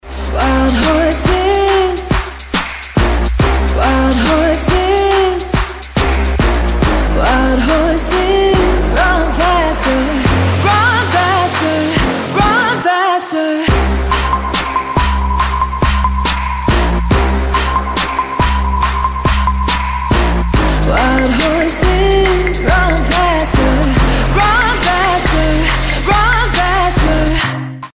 • Качество: 256, Stereo
Trap
Bass
vocal
Female voice